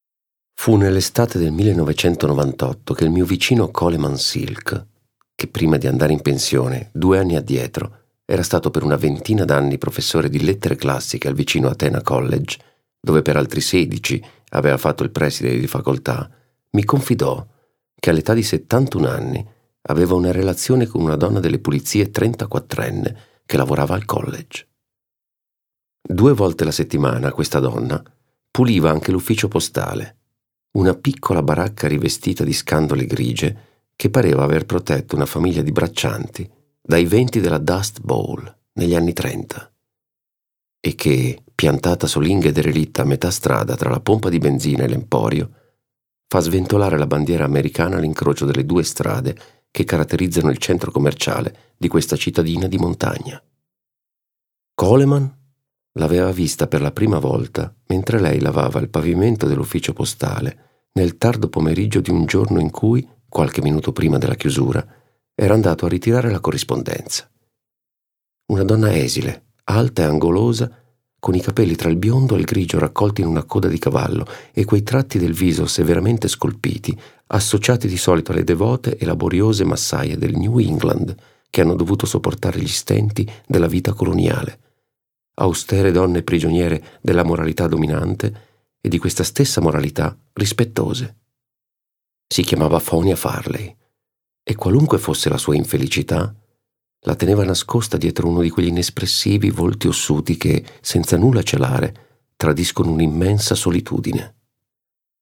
La *macchia umana / di Philip Roth ; letto da Paolo Pierobon.
Audiolibro Emons audiolibri 2017